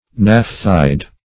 Search Result for " naphthide" : The Collaborative International Dictionary of English v.0.48: Naphthide \Naph"thide\ (n[a^]f"th[i^]d or n[a^]f"th[imac]d), n. (Chem.)